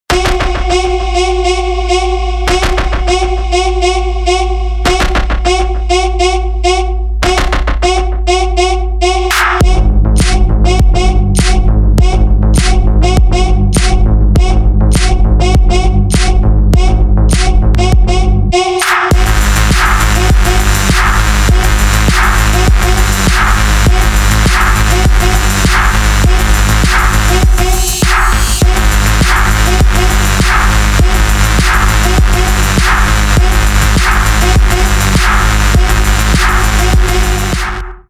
• Качество: 320, Stereo
громкие
без слов
мощный бас
Стиль: Techno, Electric